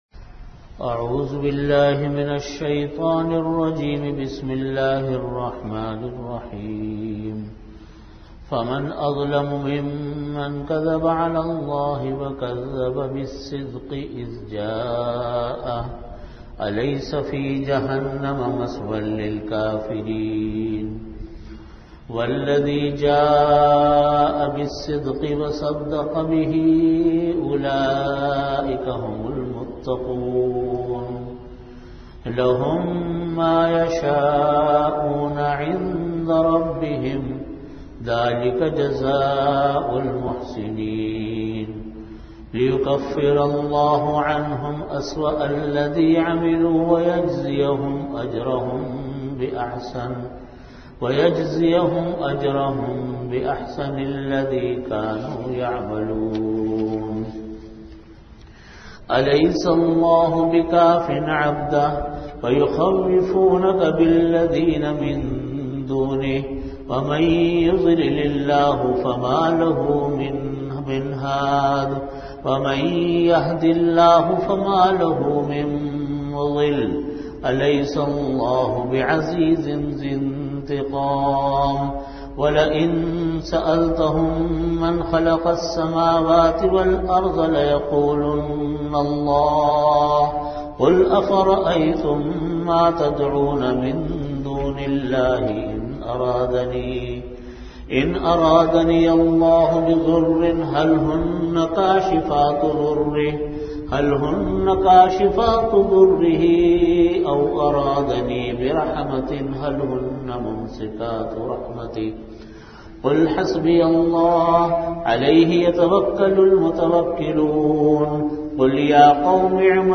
Audio Category: Tafseer
Venue: Jamia Masjid Bait-ul-Mukkaram, Karachi